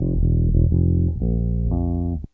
BASS 21.wav